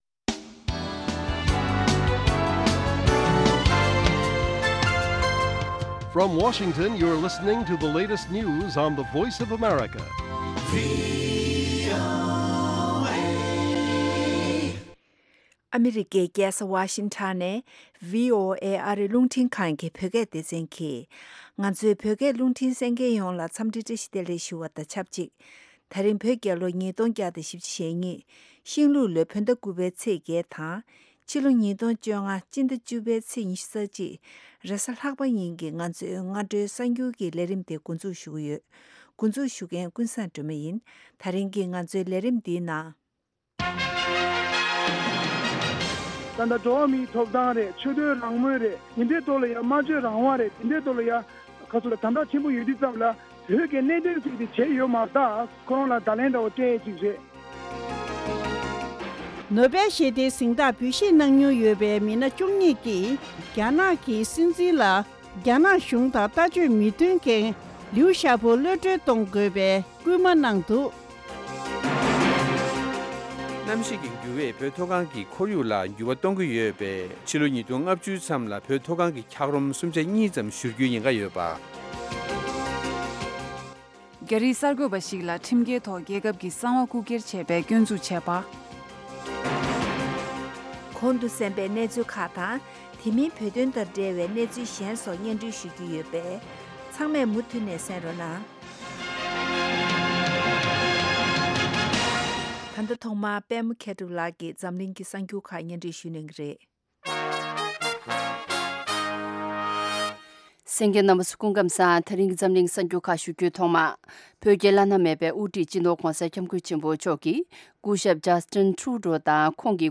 Morning News Broadcast daily at 08:00 AM Tibet time, the Morning show is a lively mix of regional and world news, correspondent reports, and interviews with various newsmakers and on location informants. Weekly features include Tibetan Current Affairs, Youth, Health, Buddhism and Culture, and shows on traditional and contemporary Tibetan music.